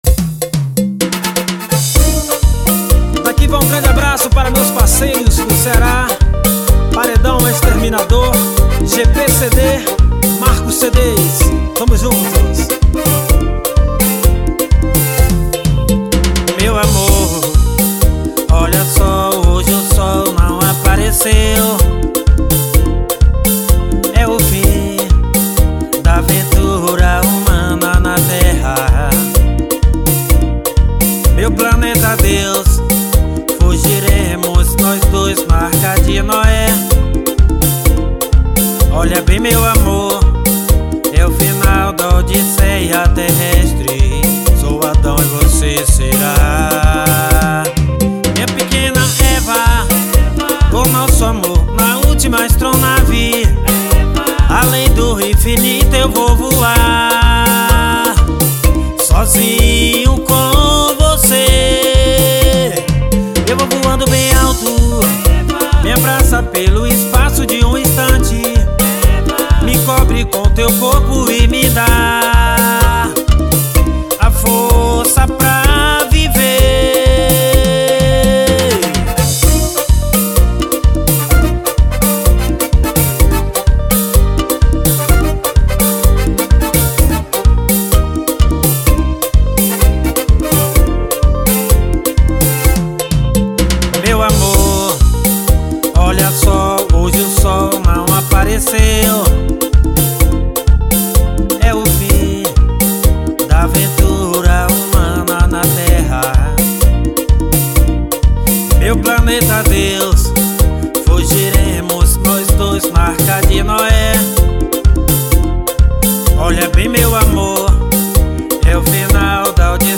EstiloSwingueira